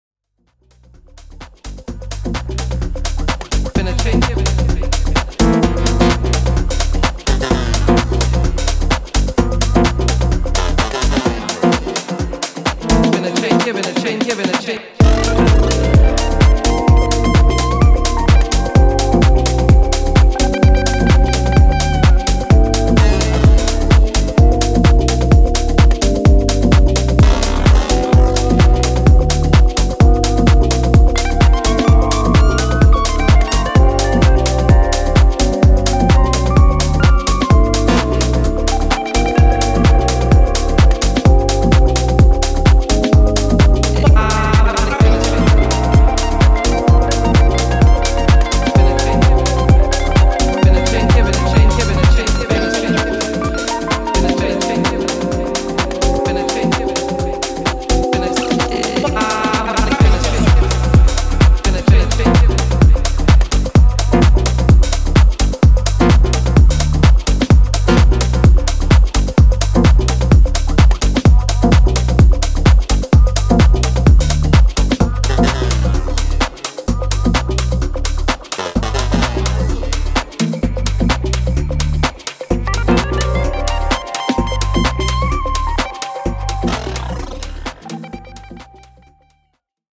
コズミックなトーンのリードシンセやジャジーなコード、トリッピーなFX等がミニマル・パーティーのピークタイムに直撃しそうな